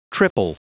Prononciation du mot triple en anglais (fichier audio)
Prononciation du mot : triple